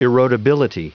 Prononciation du mot erodibility en anglais (fichier audio)
Prononciation du mot : erodibility